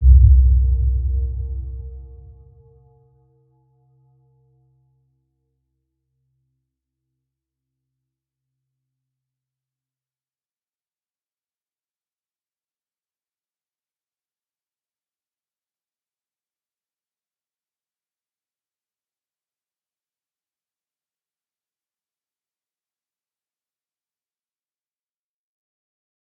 Round-Bell-C2-mf.wav